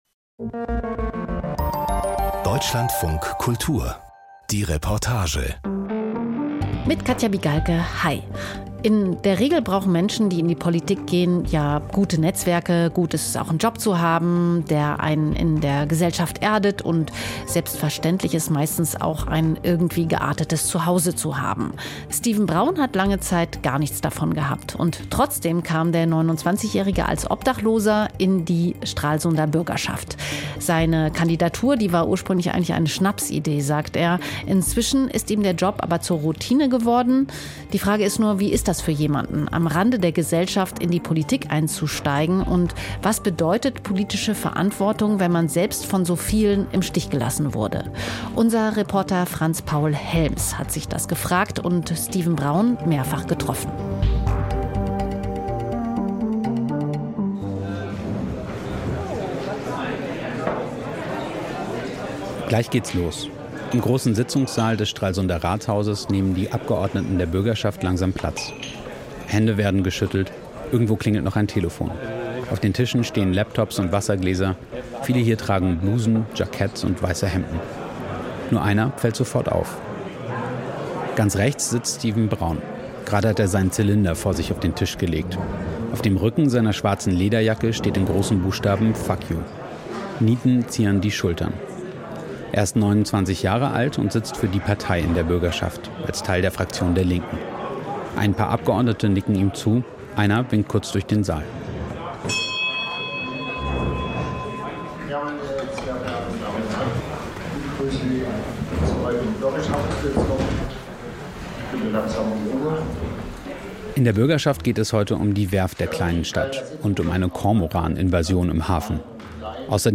Gut recherchiert, persönlich erzählt – das ist die Reportage von Deutschlandfunk Kultur.